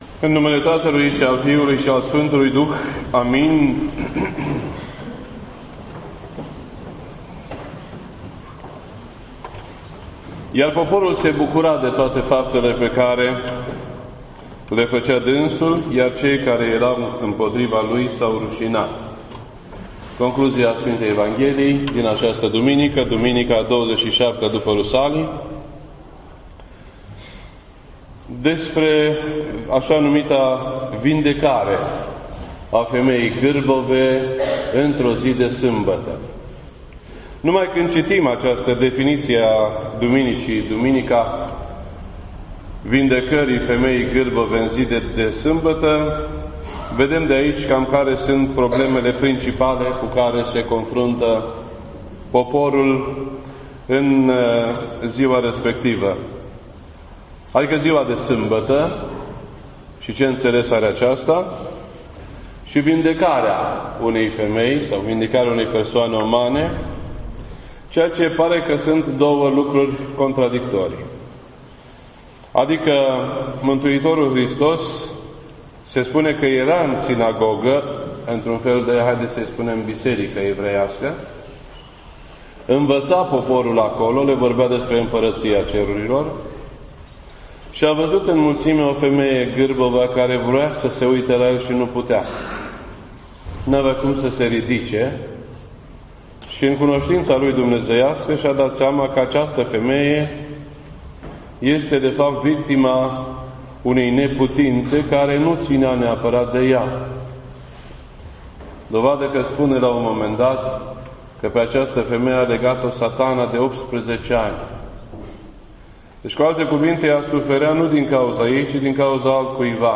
This entry was posted on Sunday, December 4th, 2016 at 10:21 AM and is filed under Predici ortodoxe in format audio.